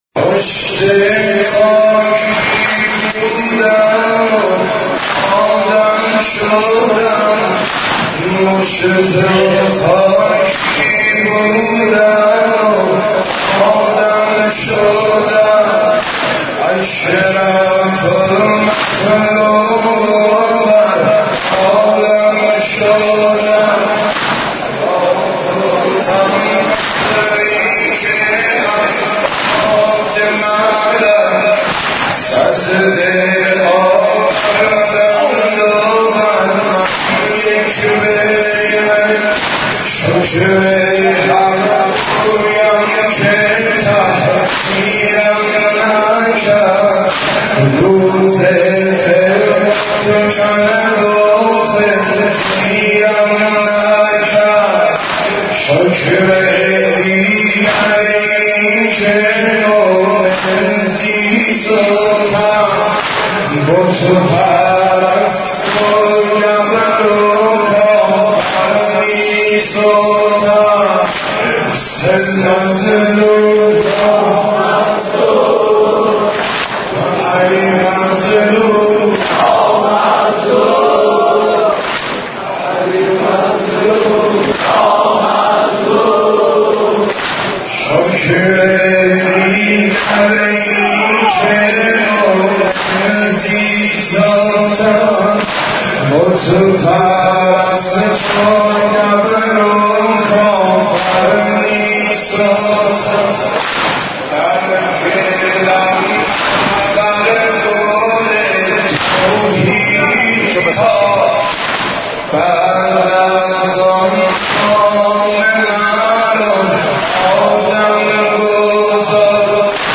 امام حسین ـ شور 1